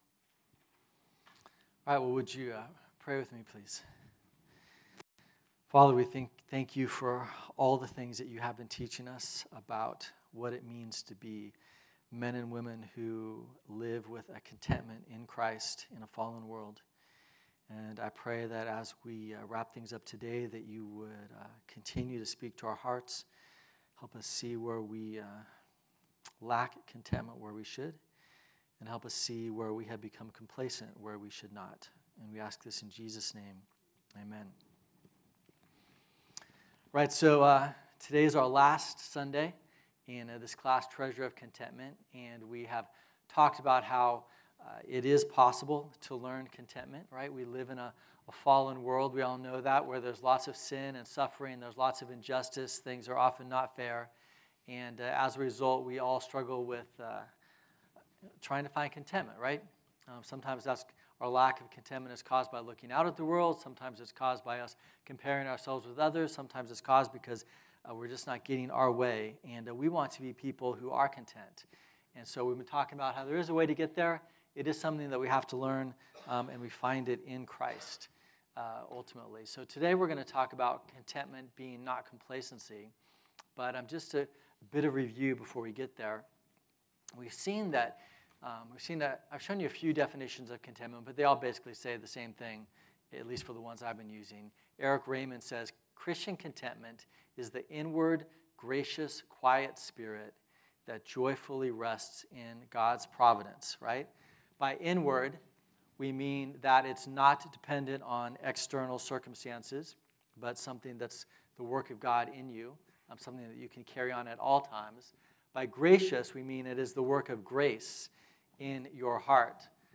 Listen to Message
Type: Sunday School